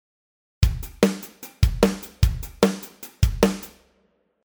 バスドラが少ない
基本パターン1からバスドラを1つ減らしたパターンです。